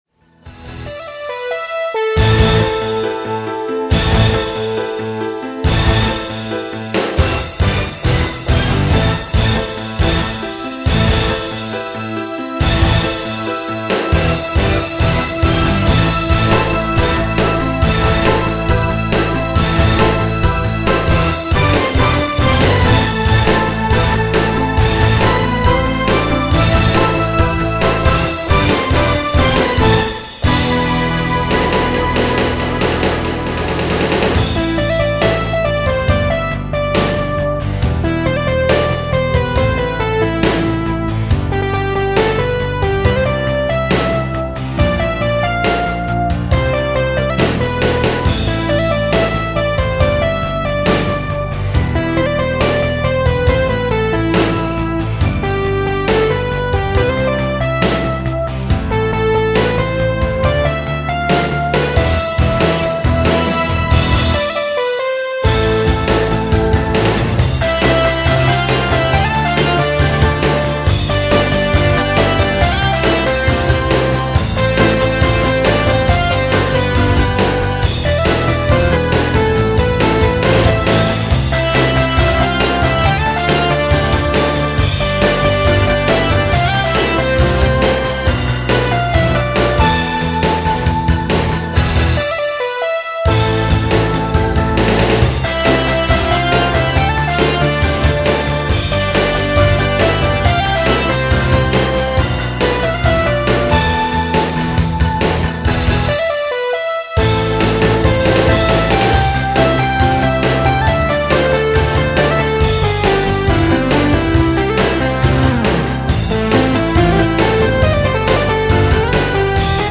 엔카의 기본 스케일 덕에 멜로디는 항상 맘에 안들지만 나름대로 분위기는 사는 듯 하다. Sound Module : Roland Sound Canvas 88 Pro Software : Cakewalk 9.0, Sound Forge 7.0 엔카 편곡 4 댓글 댓글 쓰기 목록 보기